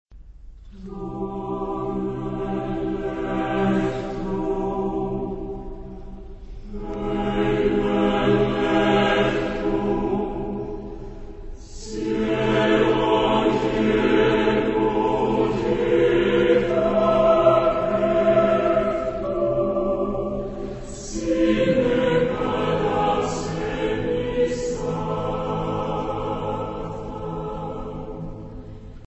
Epoque: 20th century
Genre-Style-Form: Chorale
Mood of the piece: meditative ; slow
Type of Choir: SATB  (4 mixed voices )
Tonality: A minor